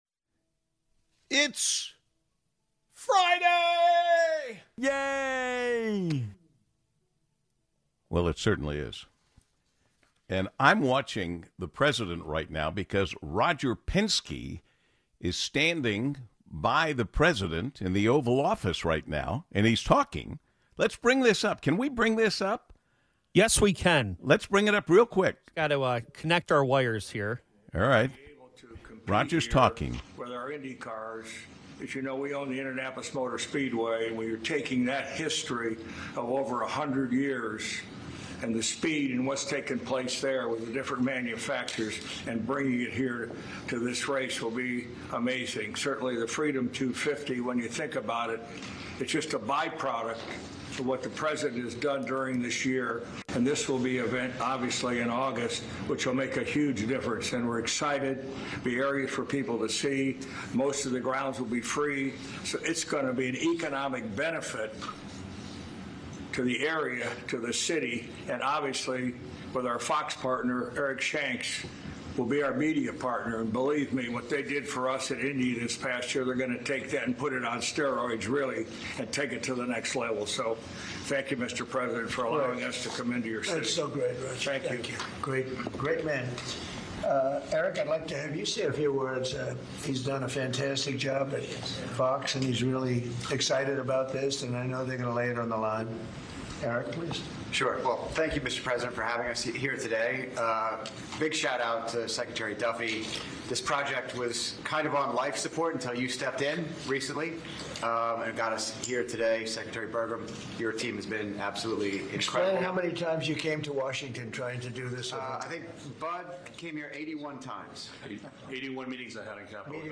National Radio Hall of Fame Inductee Paul W. Smith takes middays live and local for a show that goes in-depth with the newsmakers who are shaping the future of Southeast Michigan. Tune in as Paul connects with politicians, business and community leaders, entrepreneurs, and entertainers for longform conversations about why the efforts behind the headlines really matter. Accessible, informative, balanced, and bold, with diverse voices and thought-provoking questions that bring it all into Focus.